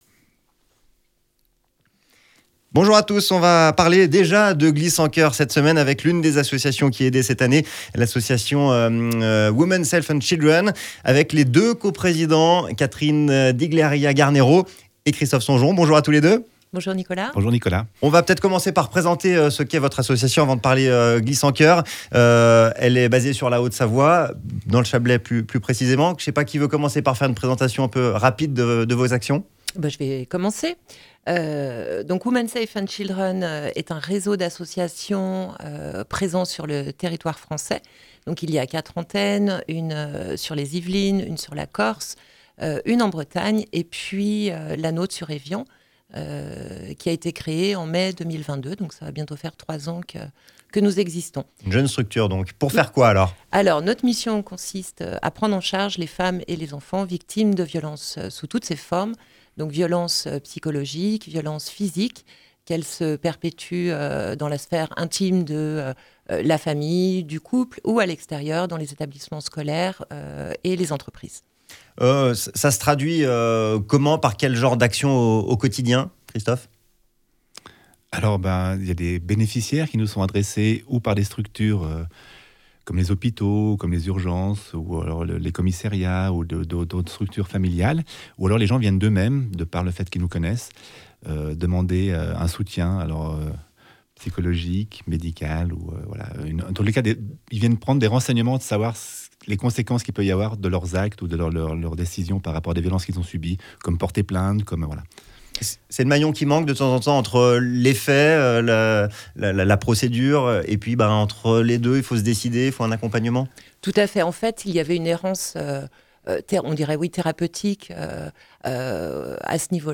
l'invité de la Rédac sur ODS Radio